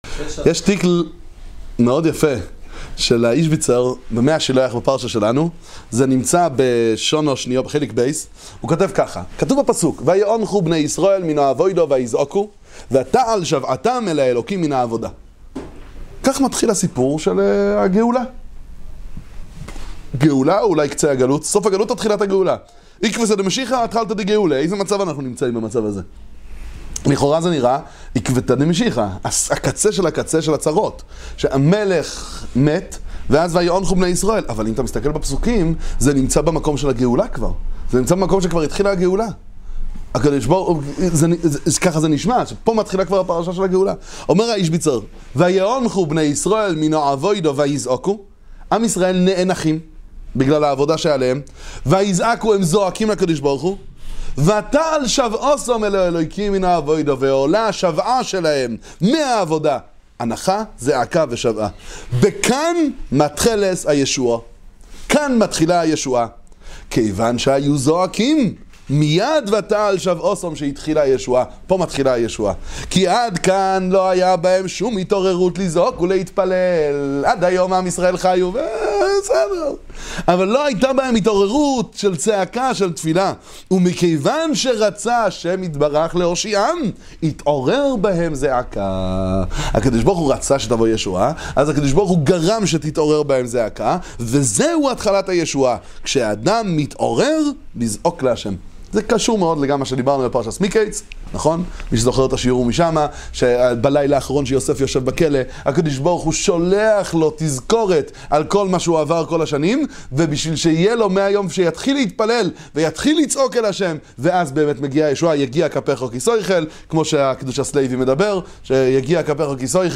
הצעקה היא תחילת הישועה – לימוד קצר בספר 'מי השילוח' איז'ביצע